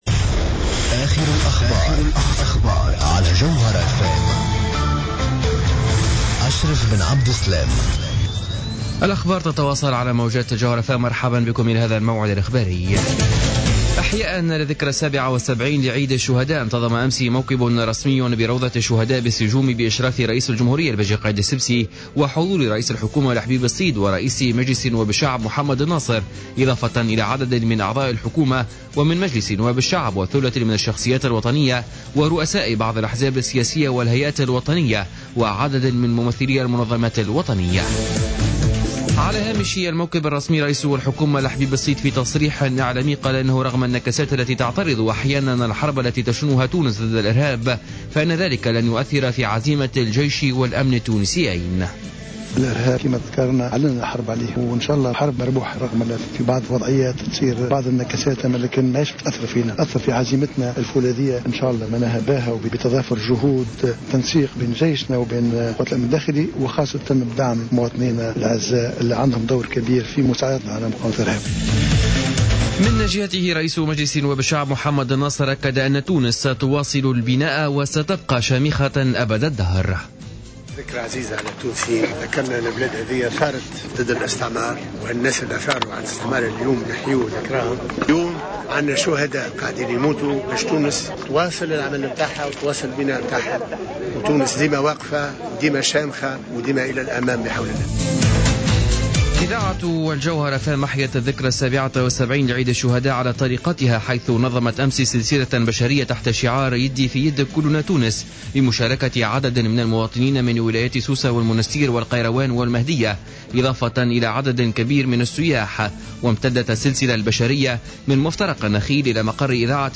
نشرة أخبار منتصف الليل ليوم الجمعة 10 أفريل 2015